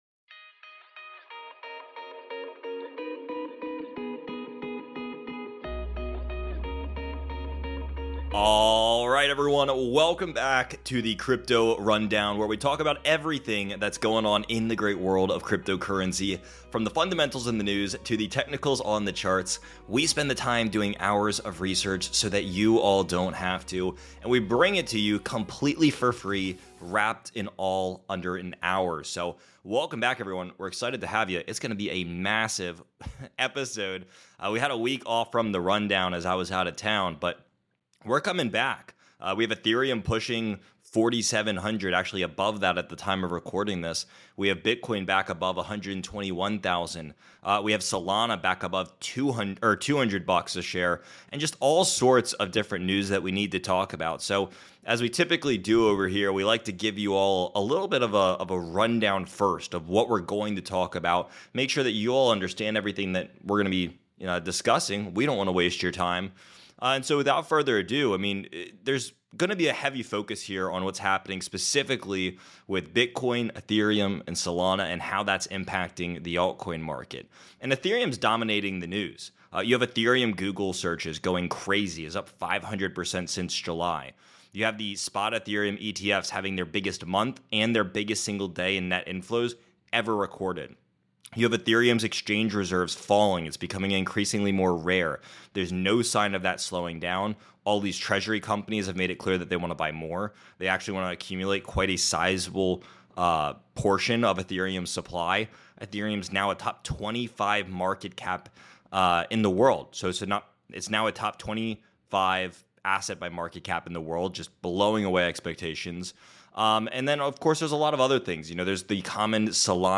Later, they take compelling listener calls, including one from a government employee with millions saved who’s still worried about the se…